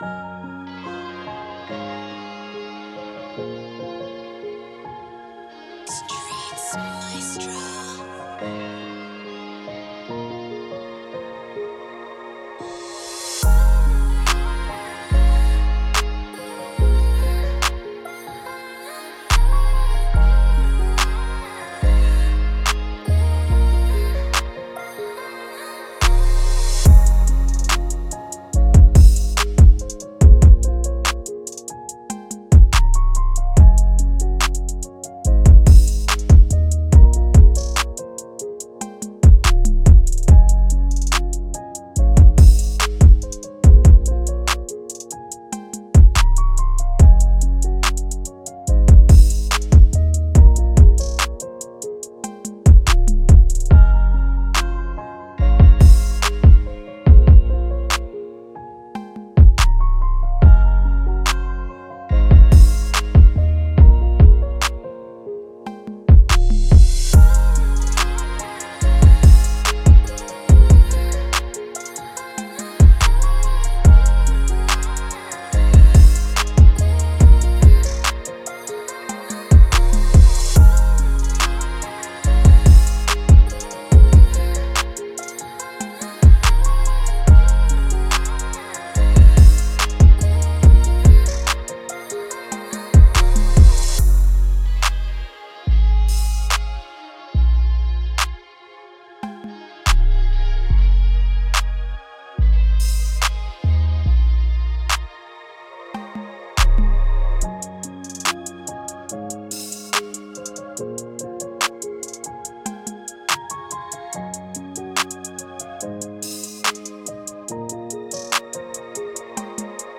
Moods: emotional, laid back, pain,
Genre: Rap
Tempo: 143
BPM 130